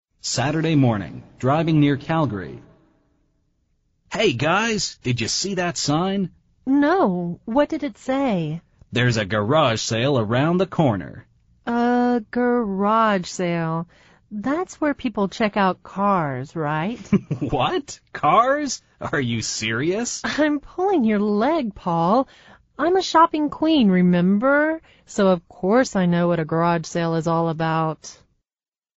美语会话实录第212期(MP3+文本):I'm pulling your leg!